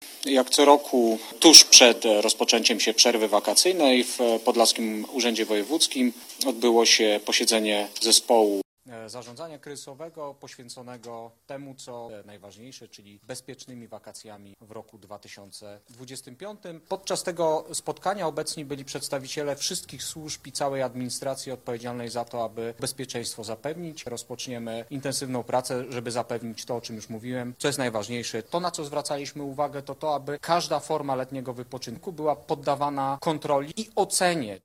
O działaniach podejmowanych w ramach akcji ,,Bezpieczne wakacje 2025″ mówił na zorganizowanej konferencji wojewoda podlaski Jacek Brzozowski.